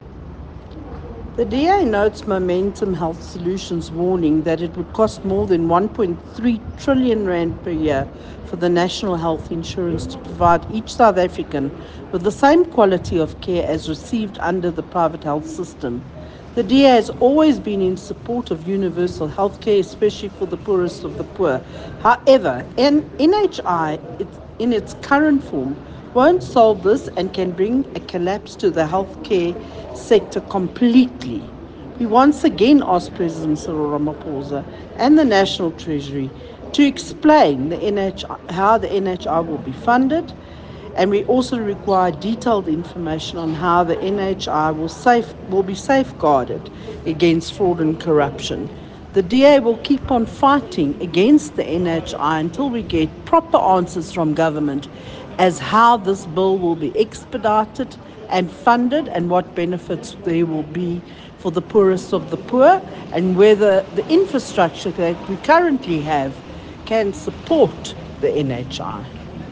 soundbite by Michele Clarke MP.